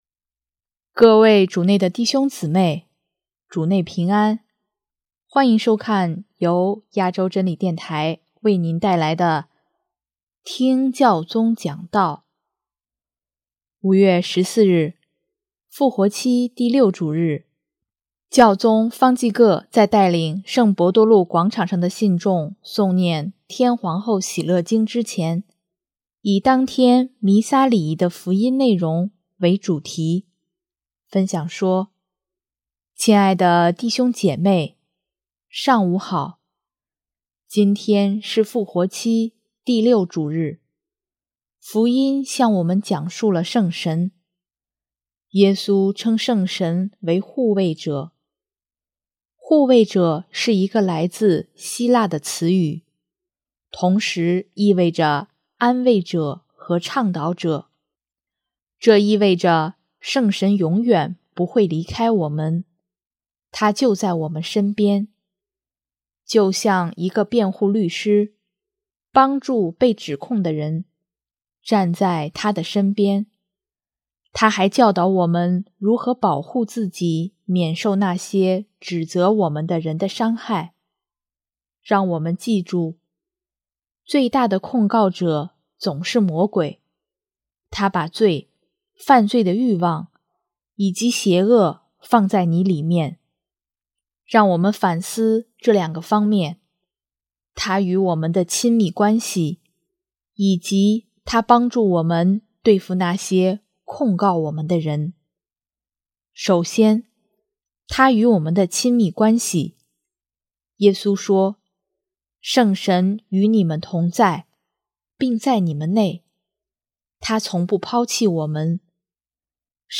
【听教宗讲道】|圣神是护慰者，保护我们免受控告者的伤害
5月14日，复活期第六主日，教宗方济各在带领圣伯多禄广场上的信众诵念《天皇后喜乐经》之前，以当天弥撒礼仪的福音内容为主题，分享说：